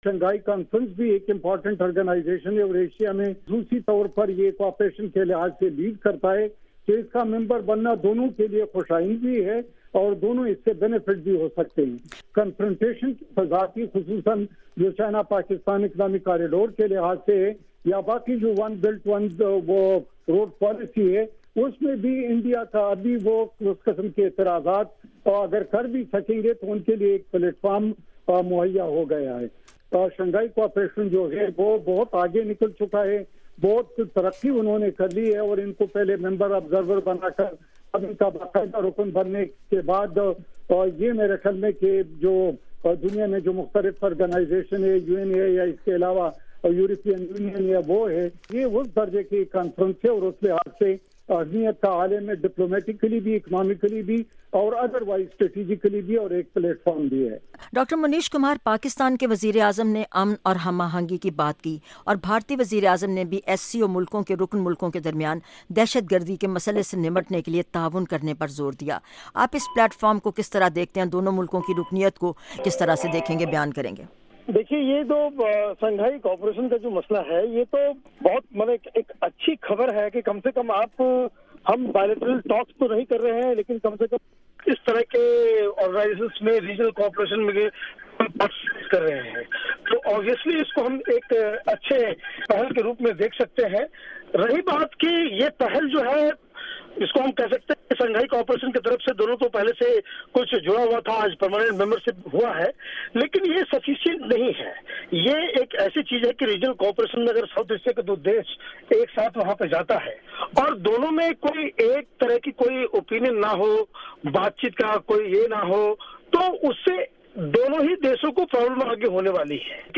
JR discussion: Impact of Pakistan India becoming SCO members